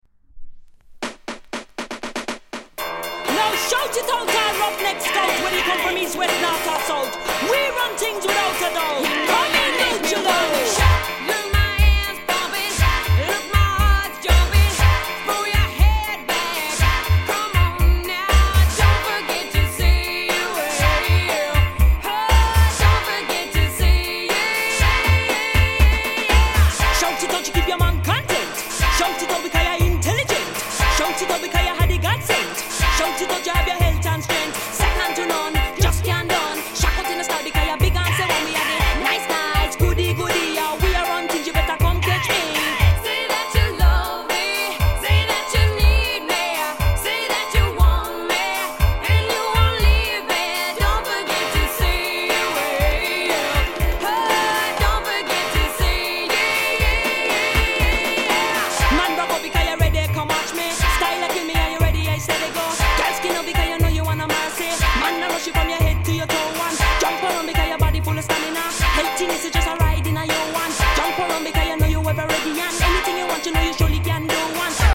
レゲエ